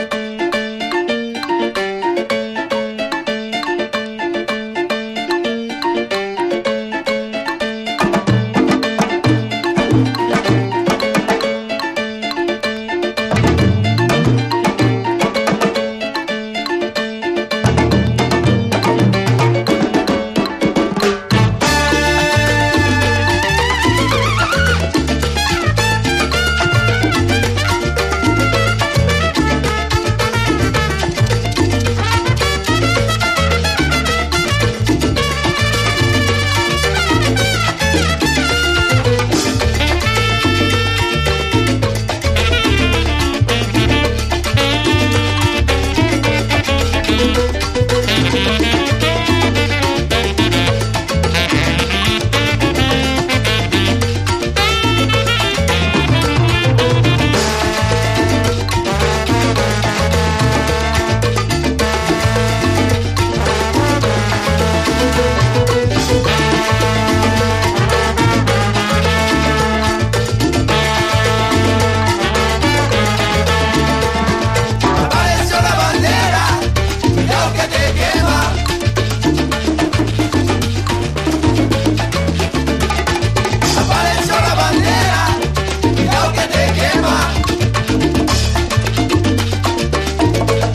2016年の国産ラテン・ファンク/灼熱キラー・マンボ！